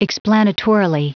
Prononciation du mot explanatorily en anglais (fichier audio)
Prononciation du mot : explanatorily